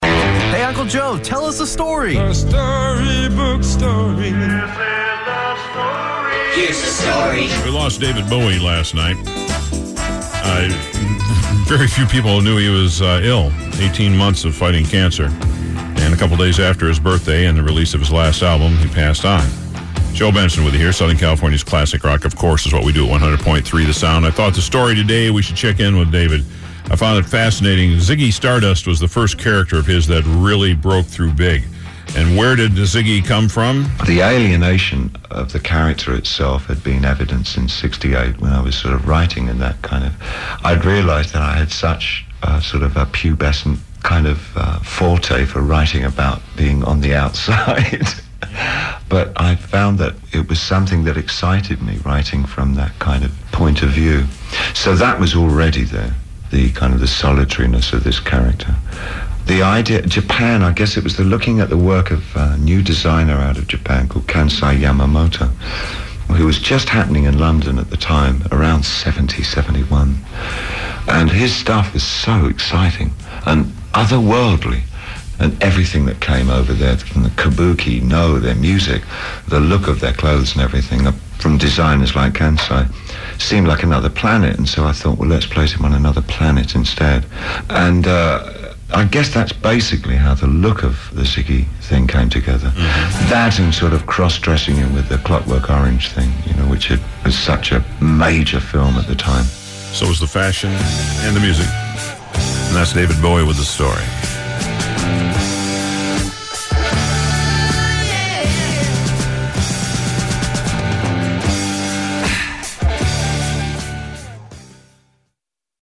David Bowie talks about his inspiration for Ziggy Stardust.